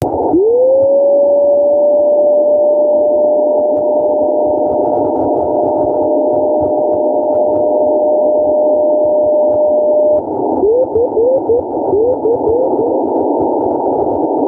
The transmission, repeated in variable periods of time, consists of the emission of a carrier lasting several seconds followed by a series of letters issued in Morse code. Often manufactured according to poor designs and /or using low-quality materials, many of them drift in frequency and transmit a poor quality signal.
CW-A1A-fishing-bouy-2.wav